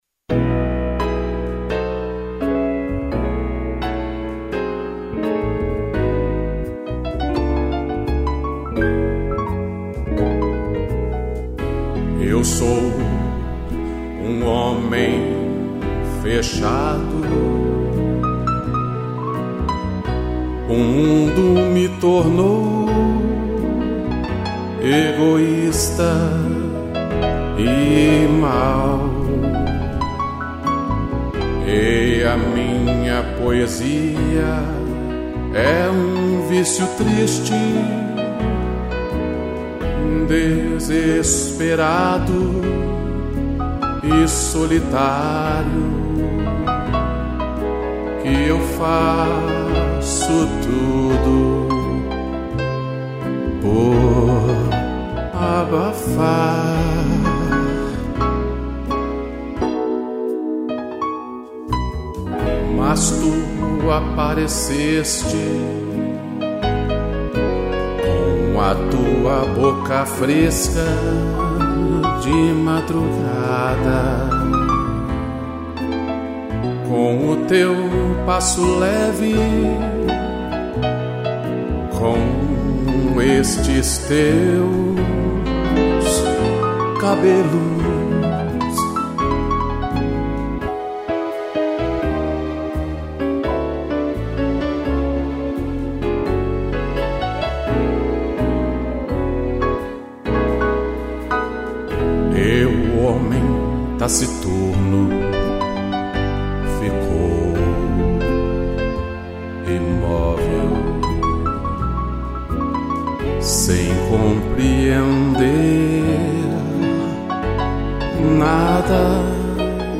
instrumental
piano